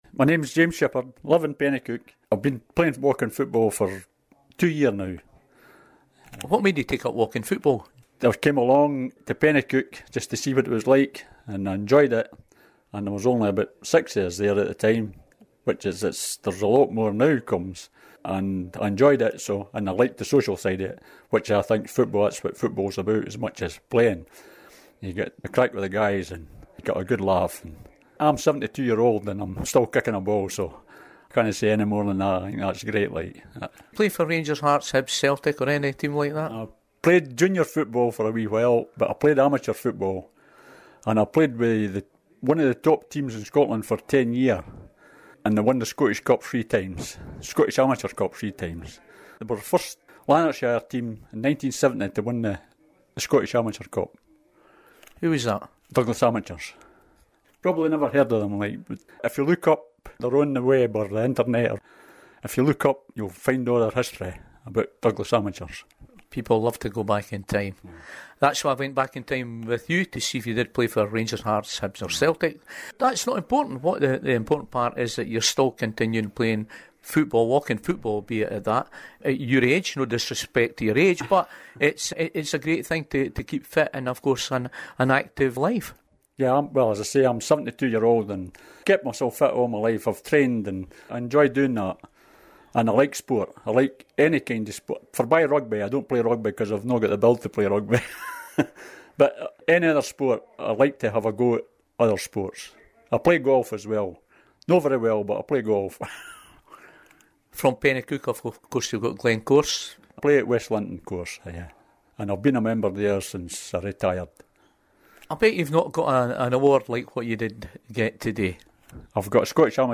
At the Bayne Memorial Hall in Loanhead, at the Player of the Year Award